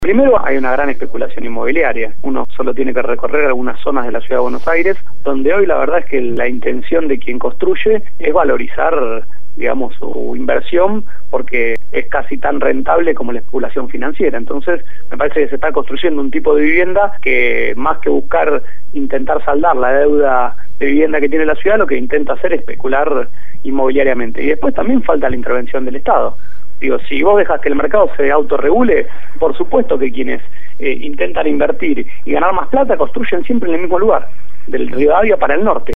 Ruanova también se refirió a la crisis habitacional en la ciudad. «Hay una gran especulación inmobiliaria. La especulación inmobiliaria es tan rentable como la especulación financiera», señaló el legislador.